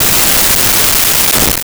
Explosion Medium 1
Explosion_Medium_1.wav